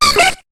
Cri de Coxy dans Pokémon HOME .